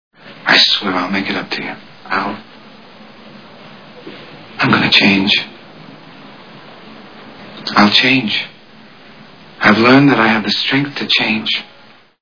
The Godfather Part 2 Movie Sound Bites